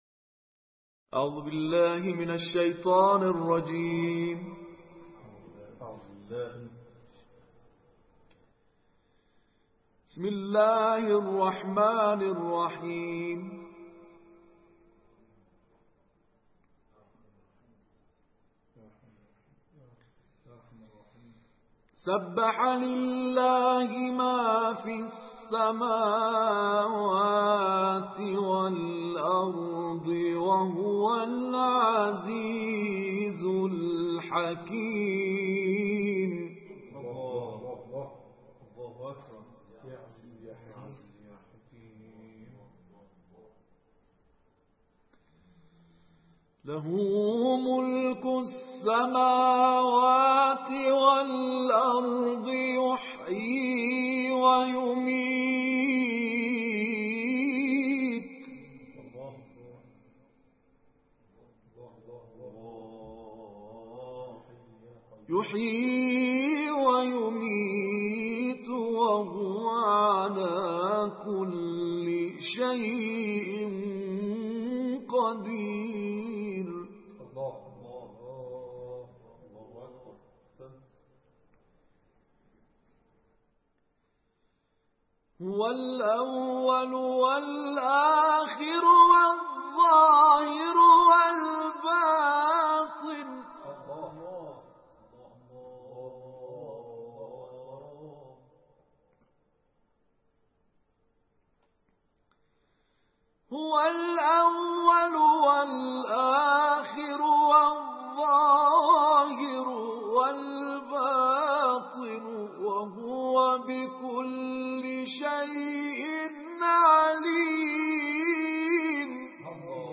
صوت | کرسی تلاوت مجازی ویژه زائران اربعین